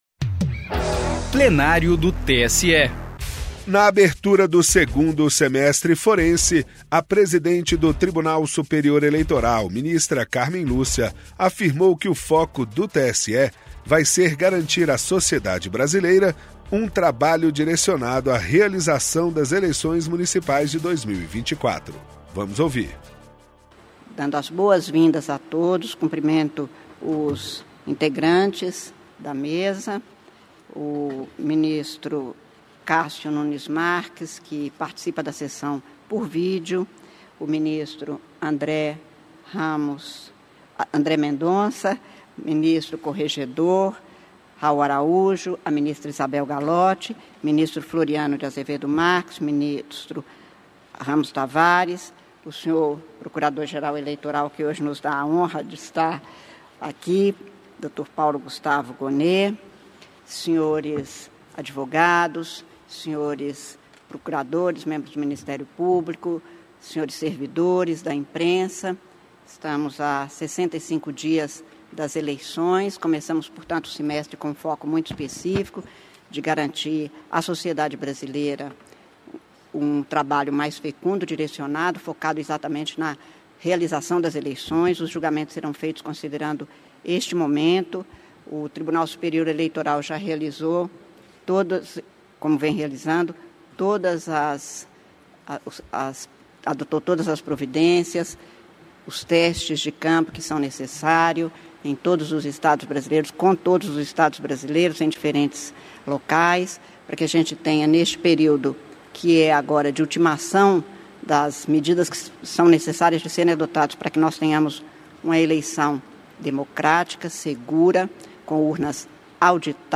Direto do Plenário - Presidente do TSE destaca foco nas Eleições 2024 ao abrir 2º semestre forense